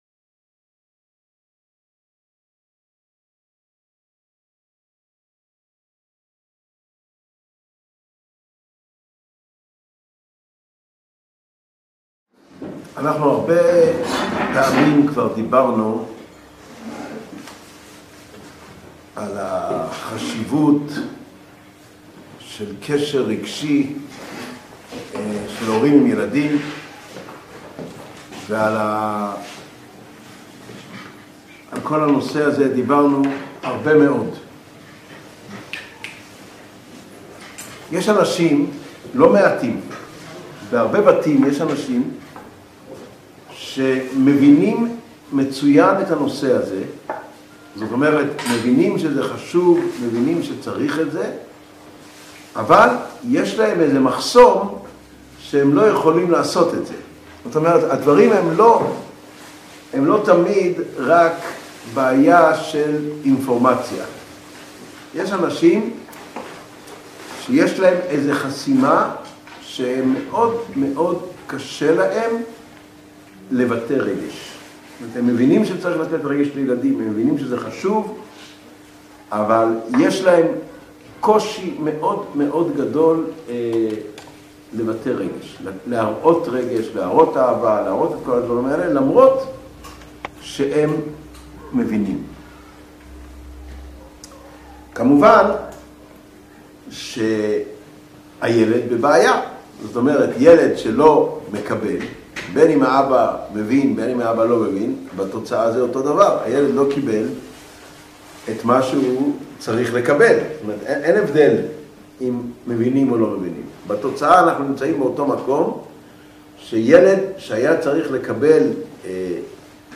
Урок № 23.